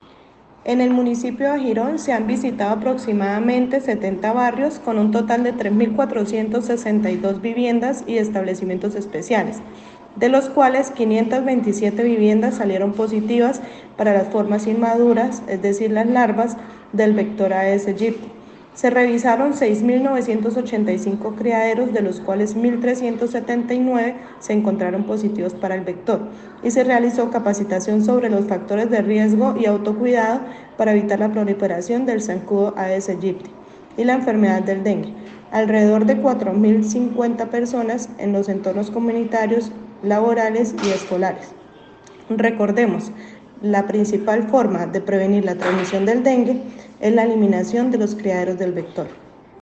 Laura Fonseca - Secretaria de Salud.mp3